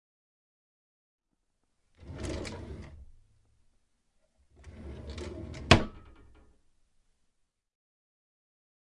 开启关闭的浴室盥洗台抽屉
描述：打开关闭浴室柜抽屉